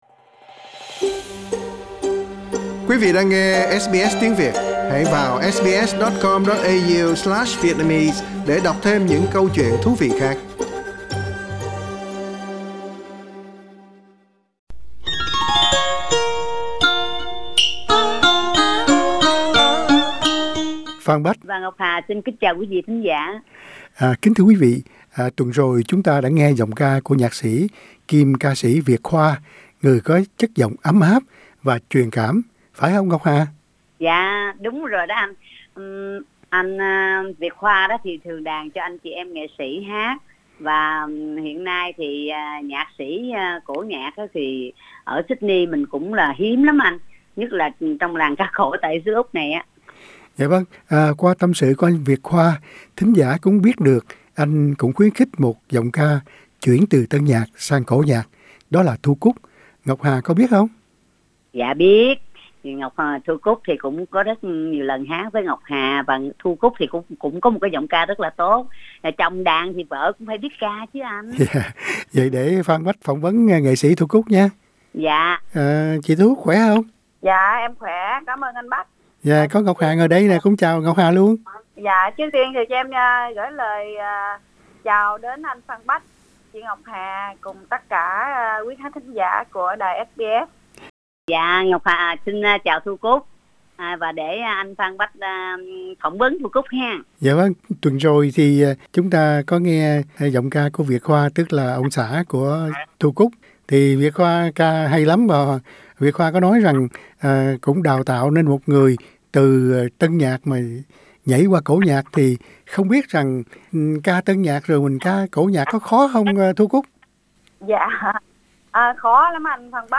Một nghệ sĩ chuyên hát tân nhạc nhưng sau đó với làn hơi phong phú đã chuyển sang lãnh vực cổ nhạc